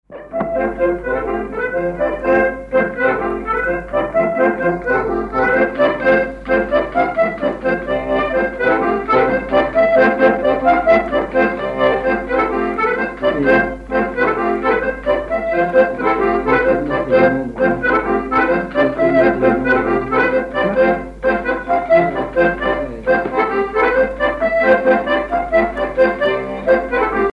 danse : branle : courante, maraîchine
accordéon diatonique
Pièce musicale inédite